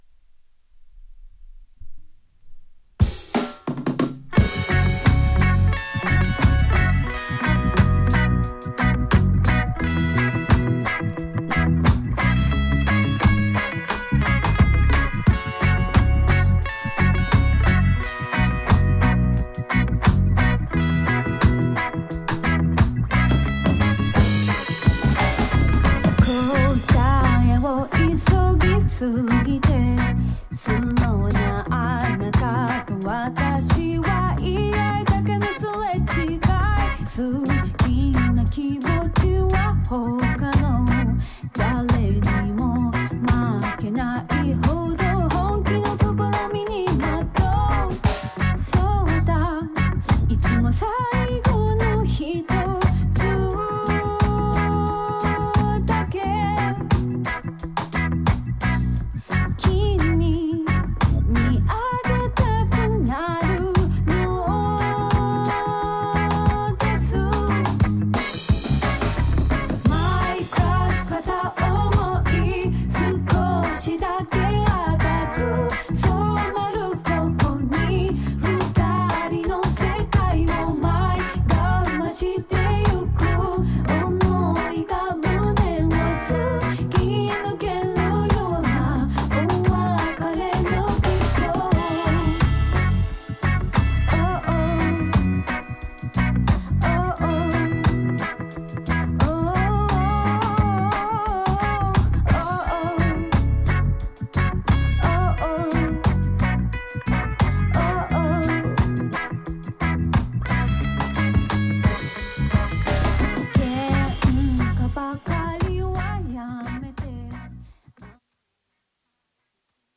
ジャンル(スタイル) JAPANESE POP